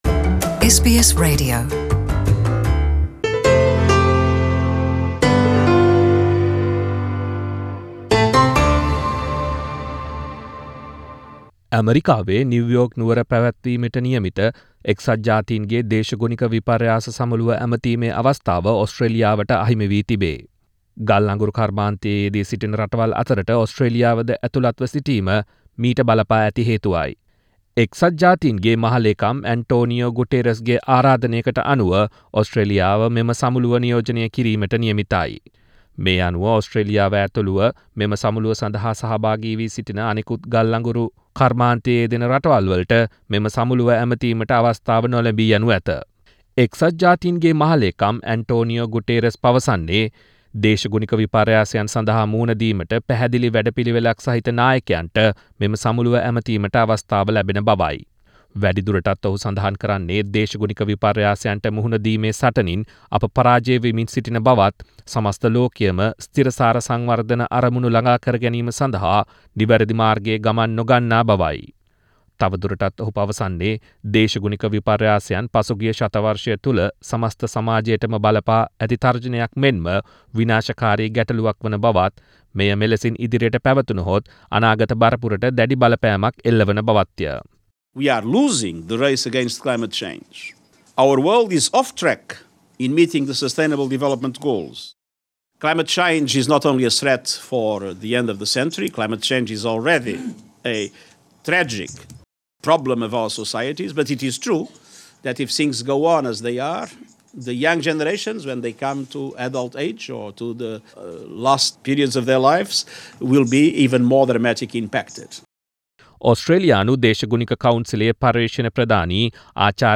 එක්සත් ජාතීන්ගේ දේශගුණික විපර්යාස සමුළුව ඇමතීමේ අවස්ථාව Australia වට අහිමී තිබේ. මේ ඒ පිලිබඳ ගෙනෙන වාර්තාවක්.